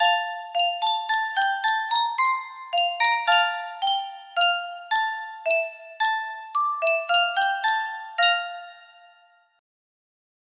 anthem1 music box melody
Full range 60